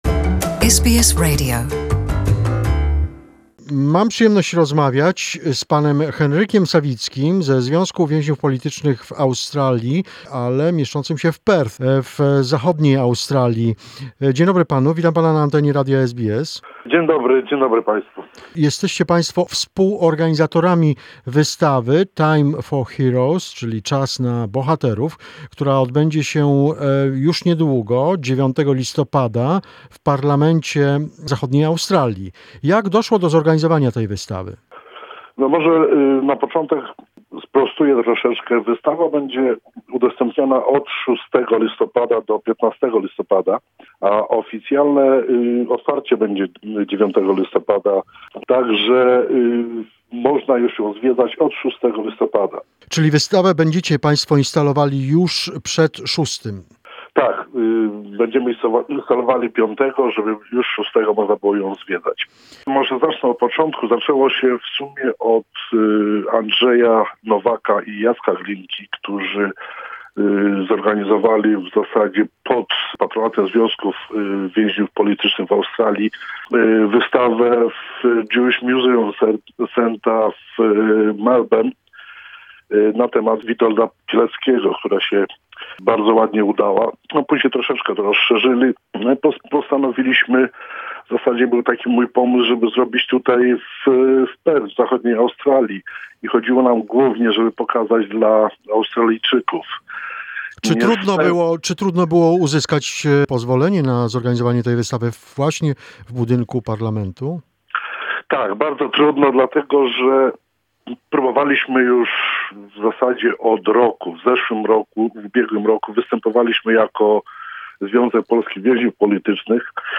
The exhibition "Time for Heroes" organized by the Pilecki Project Committee together with the Union of Former Political Prisoners will be open in WA Parliament in Perth on November 9 at 11.00 am. More in the conversation